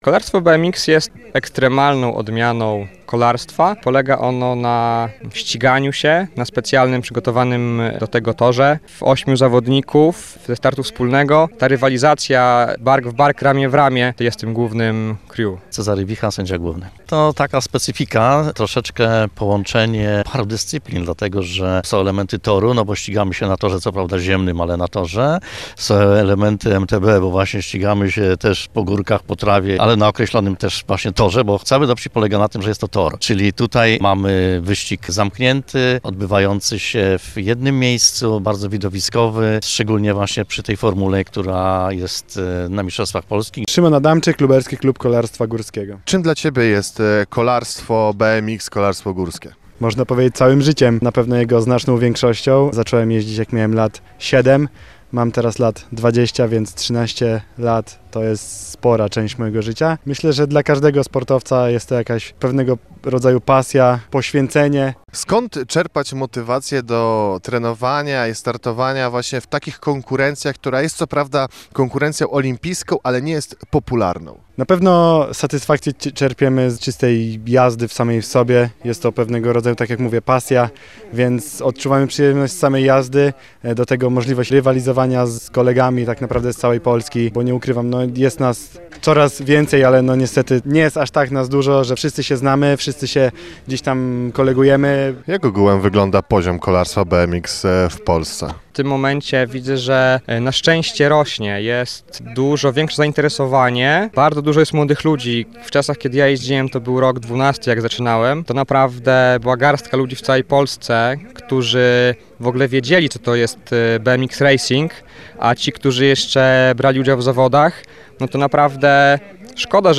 Blisko 80 zawodników wzięło udział w mistrzostwach Polski w kolarstwie BMX. Areną zmagań był tor, który znajduje się przy ul. Janowskiej w Lublinie.